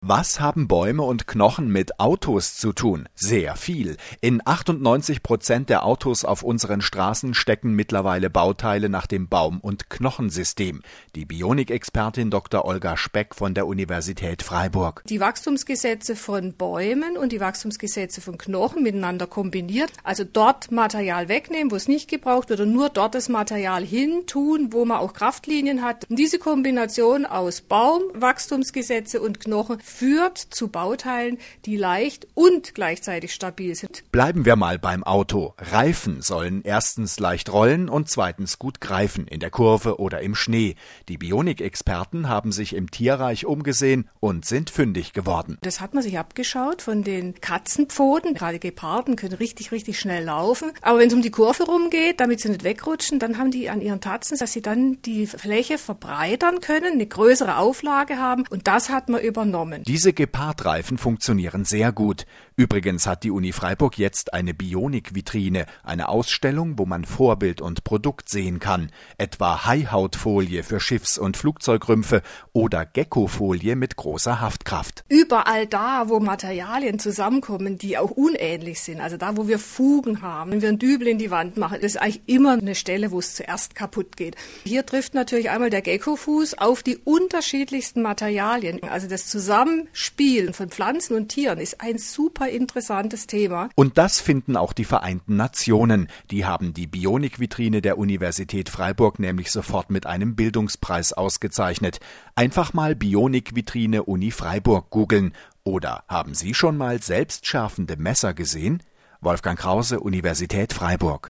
Radiobeitrag vom 10.06.2011
Das Interview